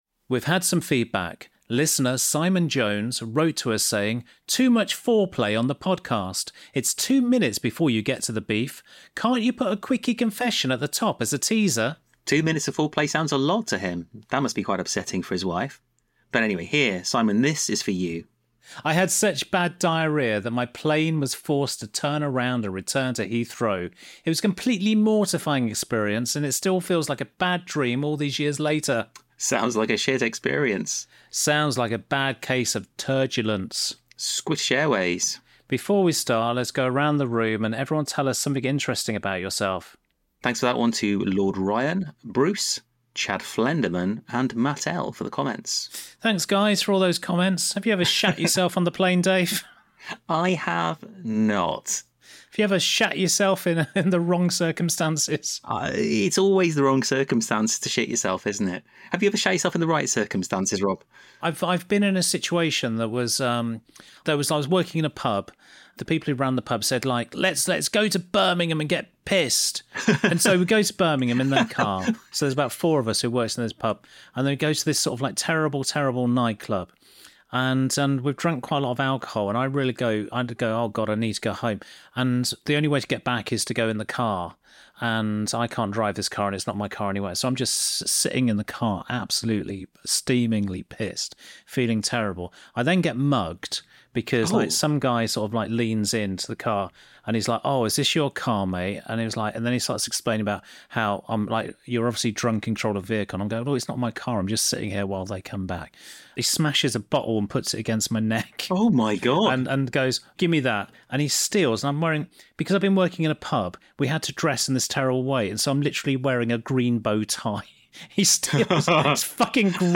We spoke to four people for this one - and in an idea totally lifted from the Graham Norton chat show, figured it would be also fun for them to speak to each other, so the format is each one talks to us directly whilst the others are muted on the Zoom call and then at the end, they can all talk to each other, like they're all on a big sofa.